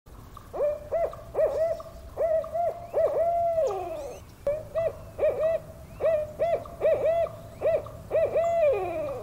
07-bagoly.mp3